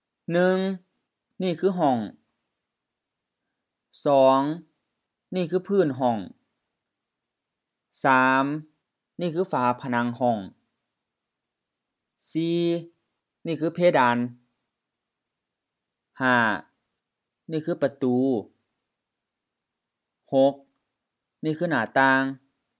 IsaanPronunciationTonesThaiEnglish/Notes
ห้อง hɔŋ LF ห้อง room
เพดาน phe:-da:n HR-M เพดาน ceiling